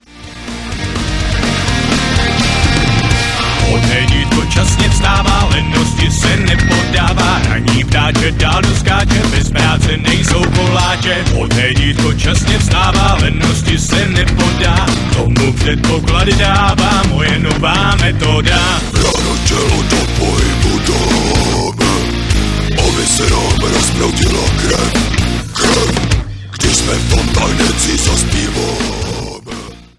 v originálním podání dnešních tvrdých kapel